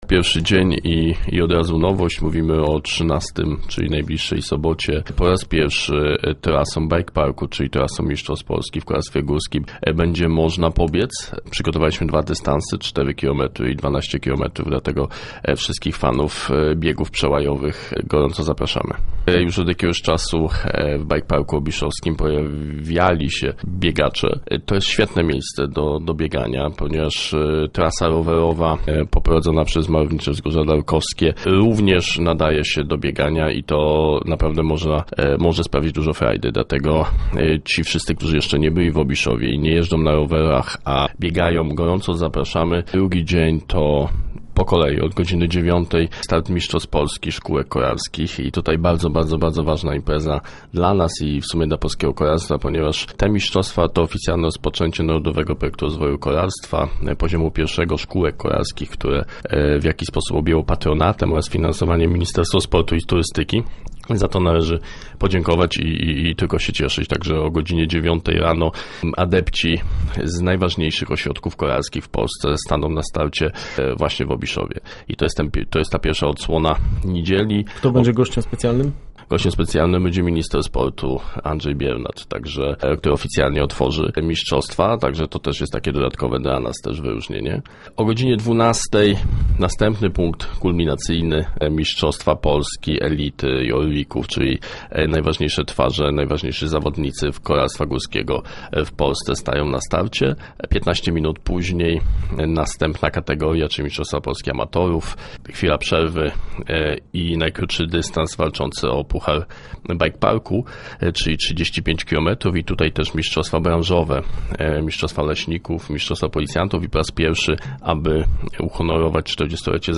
był gościem w studiu Radia Elka.